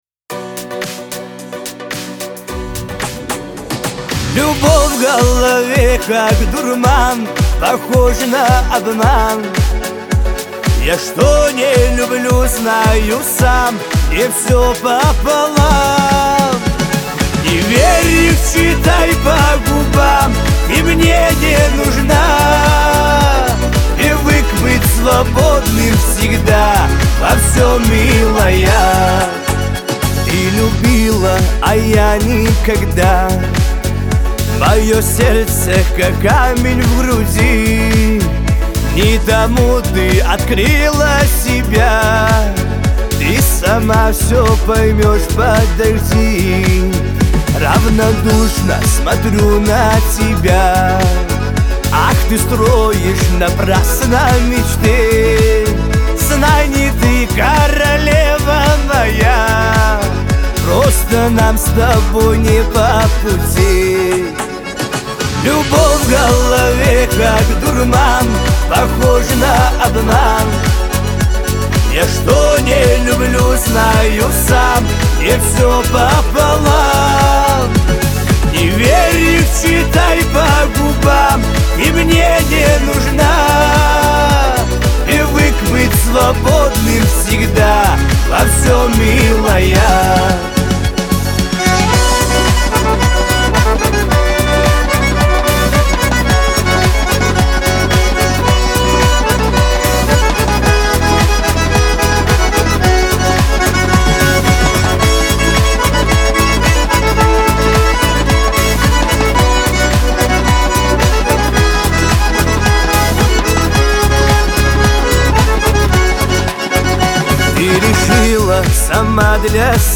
Кавказ – поп